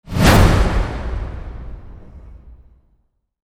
metal_bang2.mp3